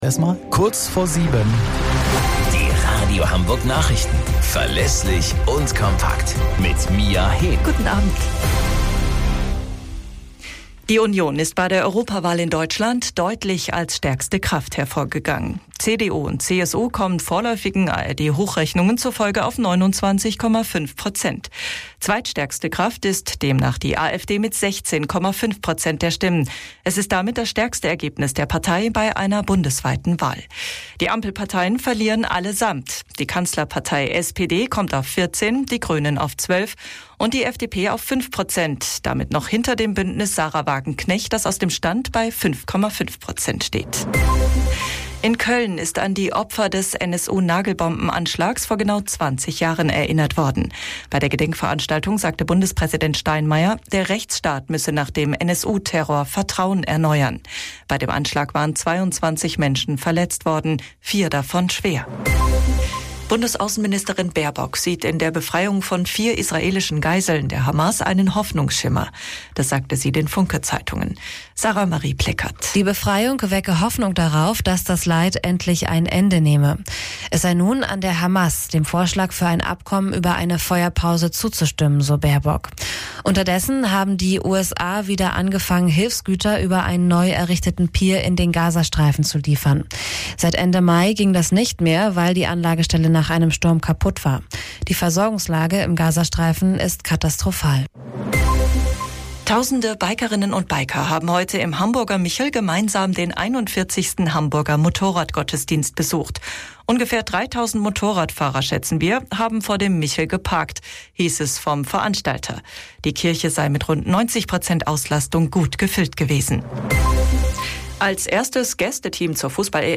Radio Hamburg Nachrichten vom 10.06.2024 um 02 Uhr - 10.06.2024